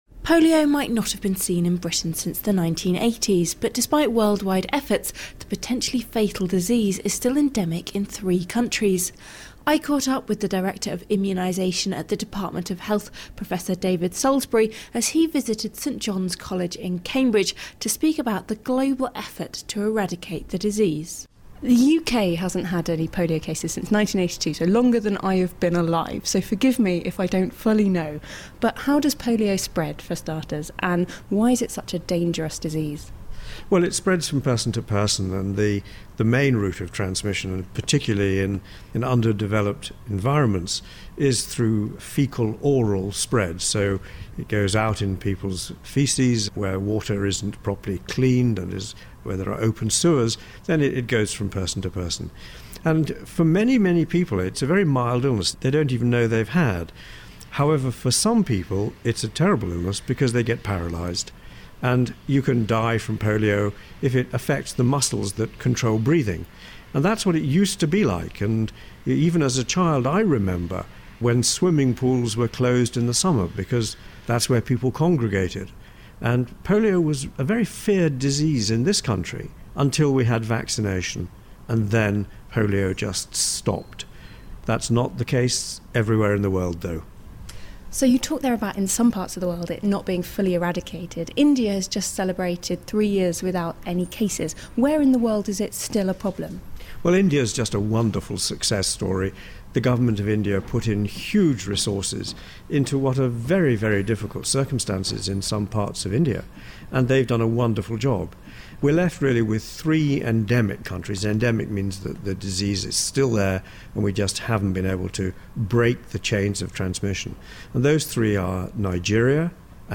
caught up with the Director of Immunisation at the Department of Health, Professor David Sailsbury as he visited St Johns college in Cambridge to speak about the global effort to eradicate the disease.